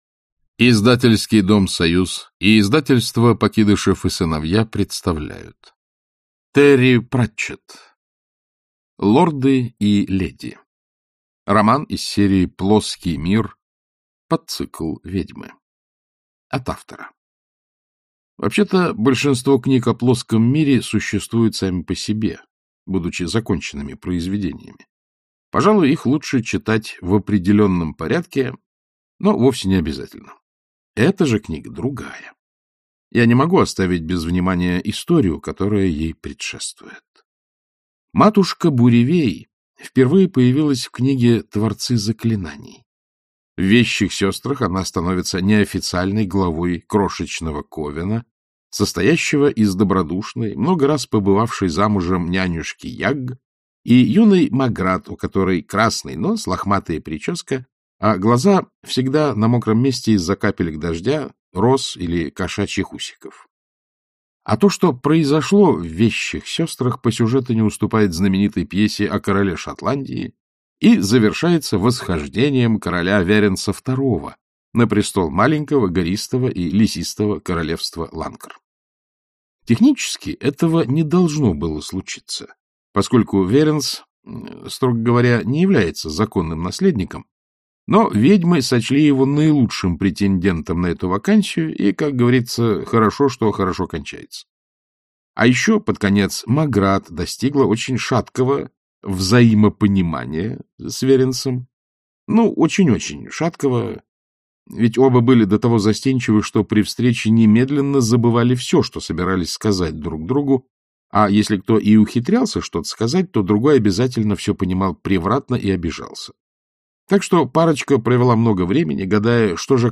Аудиокнига Лорды и леди | Библиотека аудиокниг